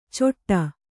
♪ coṭṭa